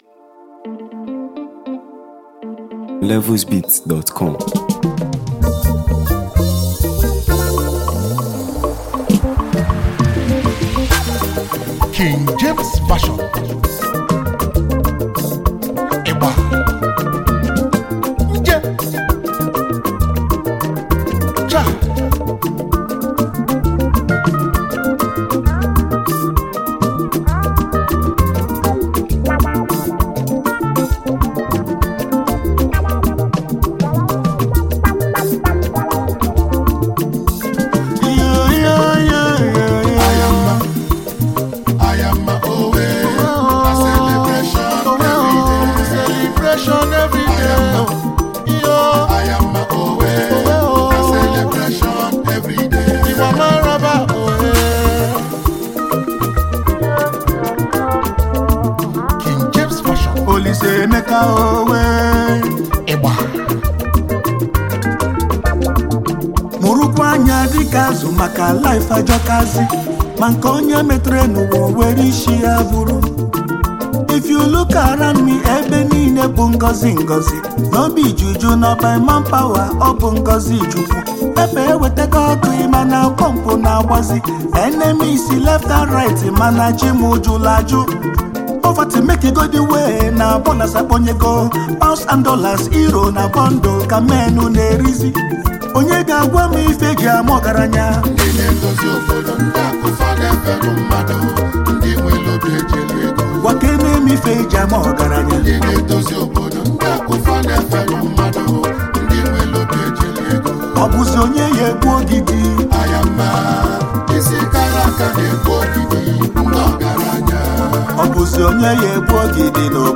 Talented music collective
uplifting and energetic track